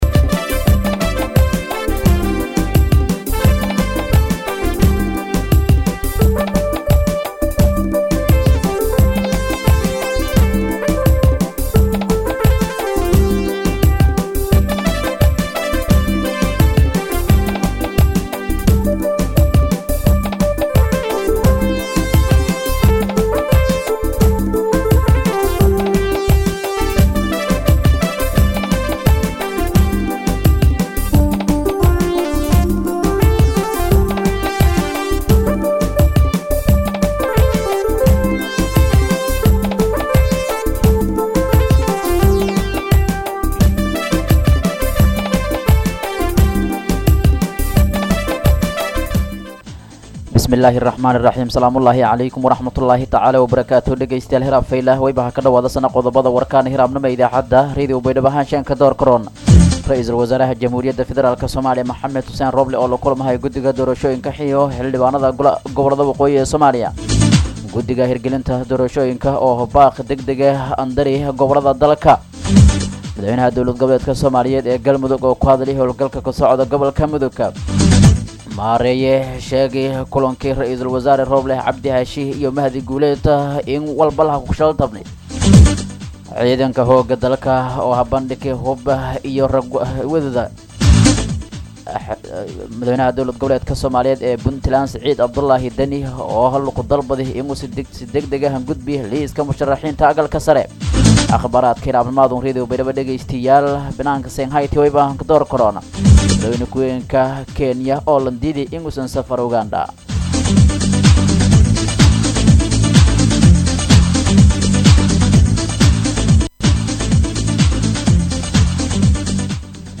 warka-subaxnimo-.mp3